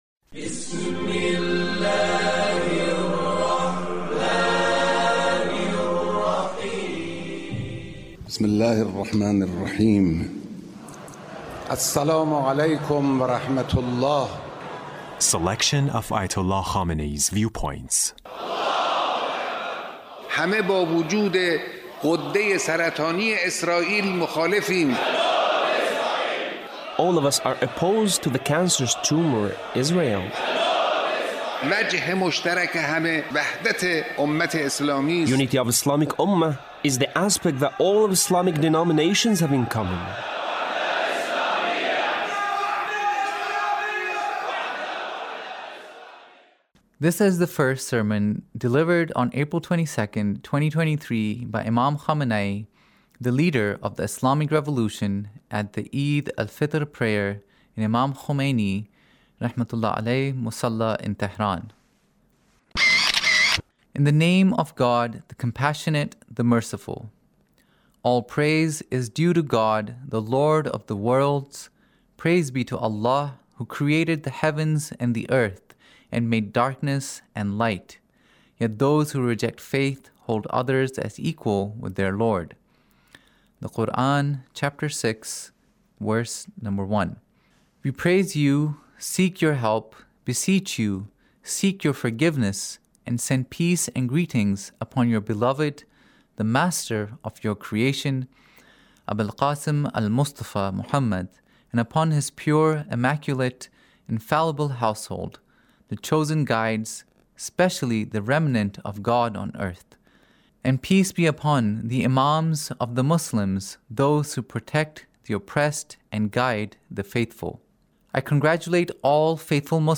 Leader's Speech On Eid Al Fitr